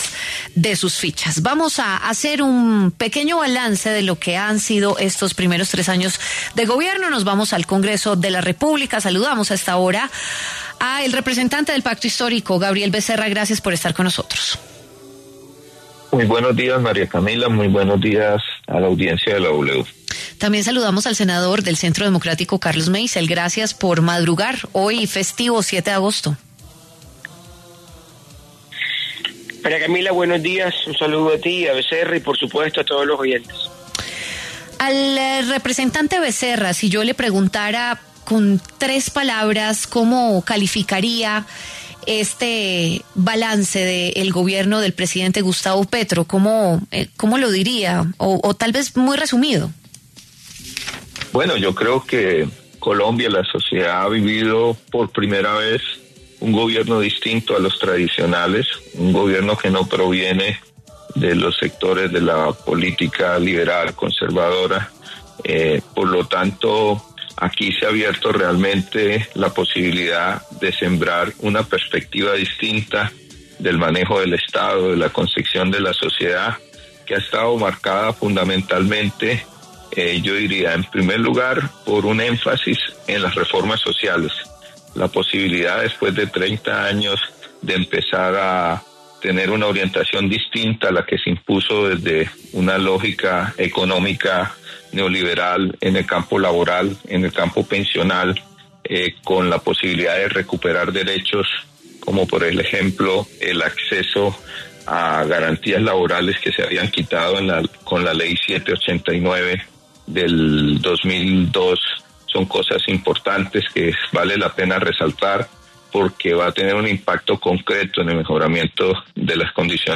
El senador Carlos Meisel y el representante Gabriel Becerra, debatieron sobre los cambios que ha traído al país el Gobierno Petro.